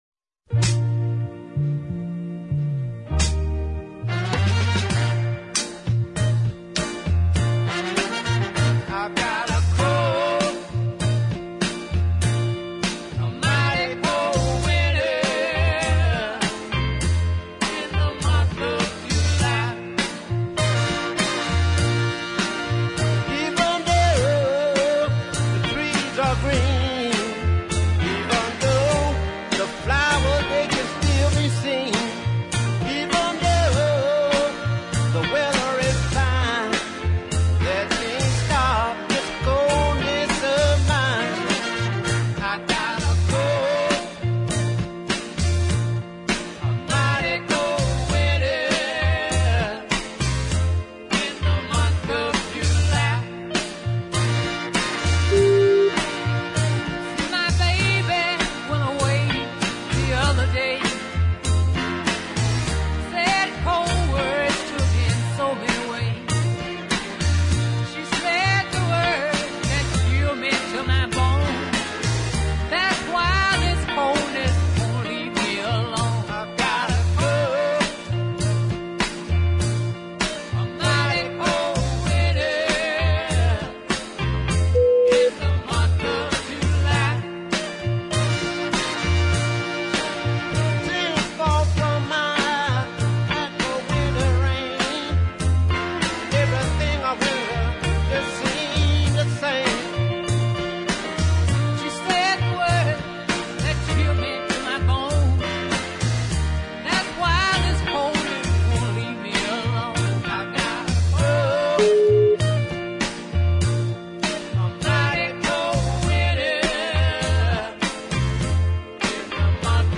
with overdubbed horn charts